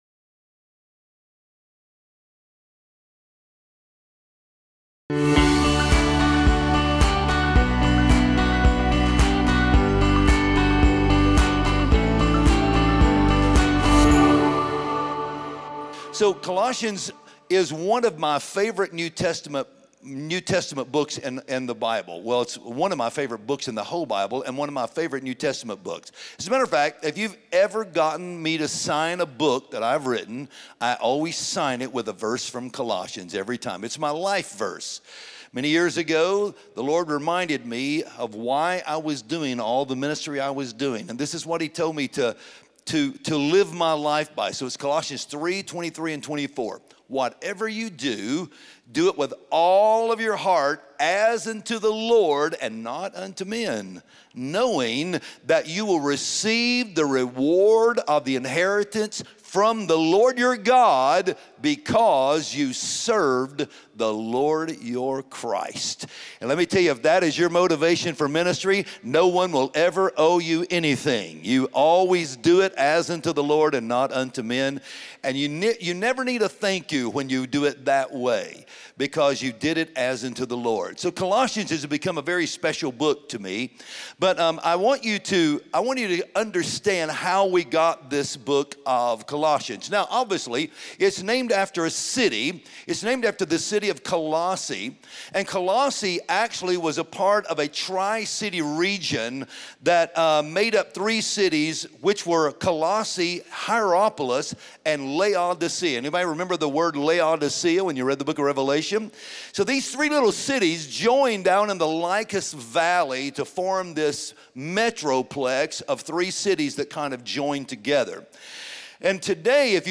Join us this week for the sermon “In the Name of Jesus.”